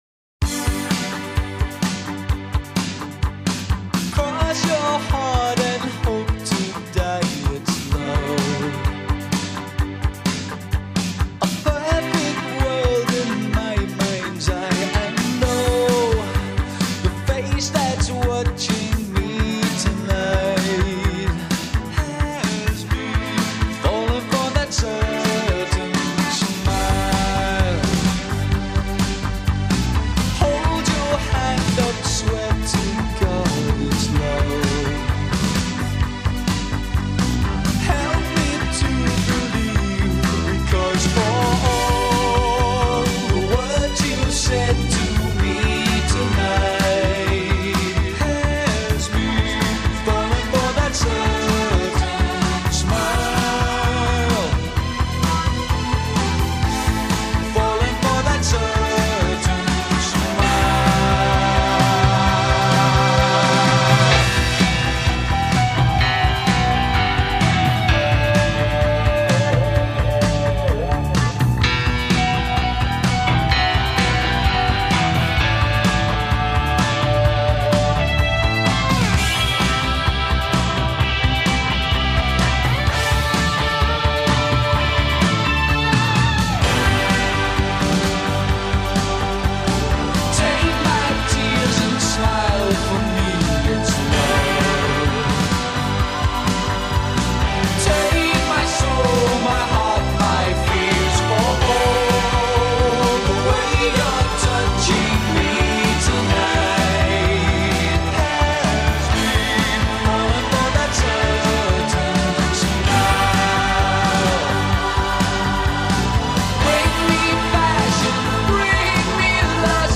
Género: Rock.